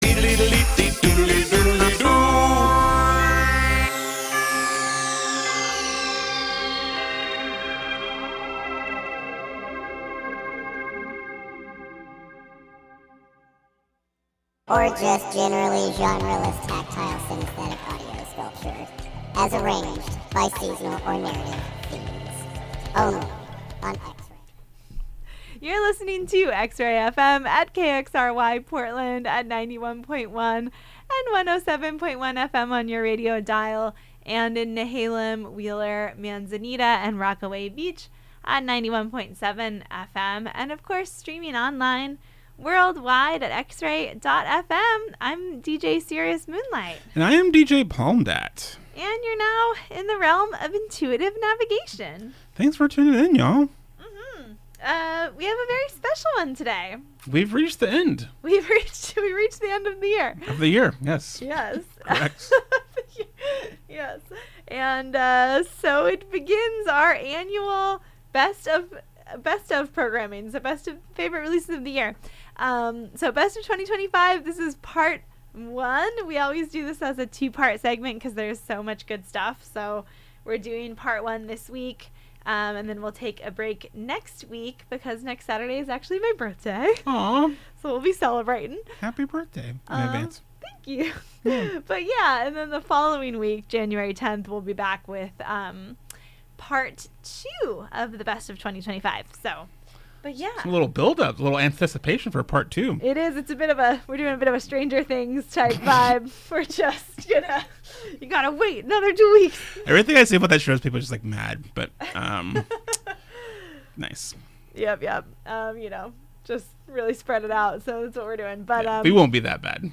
The latest in electronic pop, R&B, and other soulful music.
Feels-talk, moon-talk, and sultry jams.